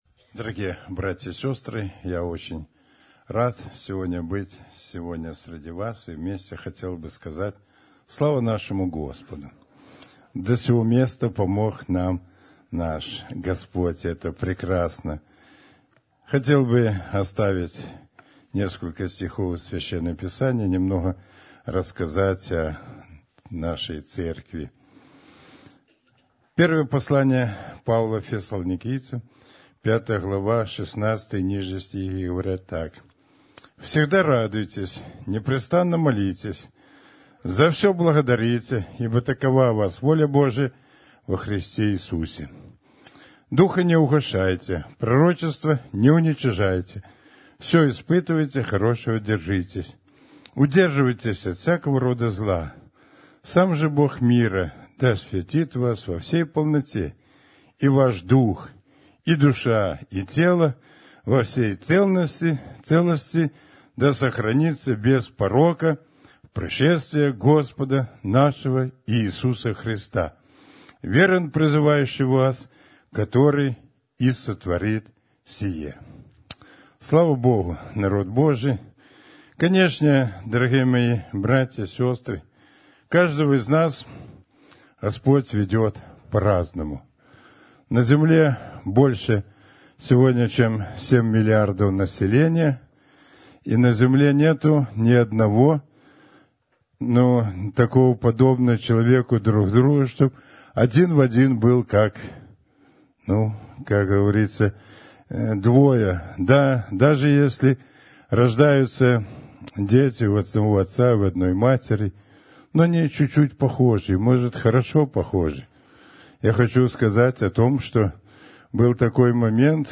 Праздничное богослужение, посвящённое 25-летию церкви.